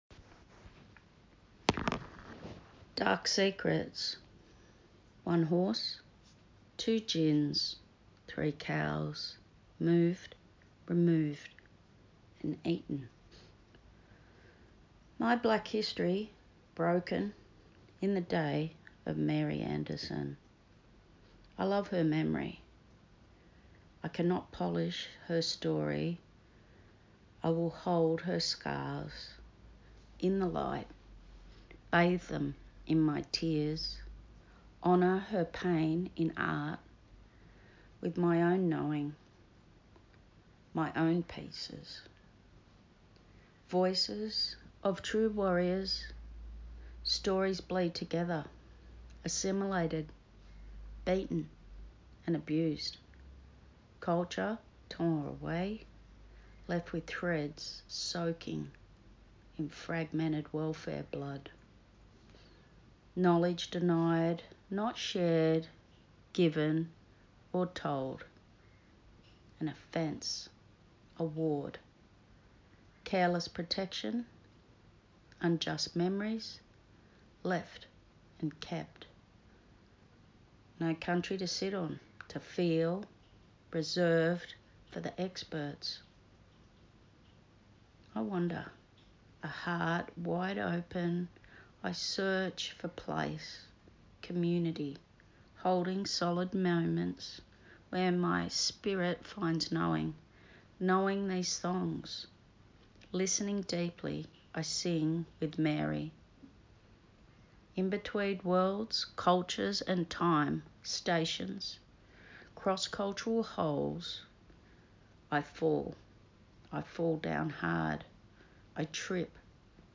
Karla Dickens Dark Secrets. A poem written and recited by the artist